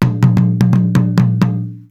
PERC 14.AI.wav